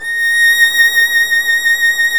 Index of /90_sSampleCDs/Roland L-CD702/VOL-1/STR_Violin 1 vb/STR_Vln1 % + dyn